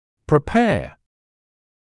[prɪ’peə][при’пэа]подготавливать; препарировать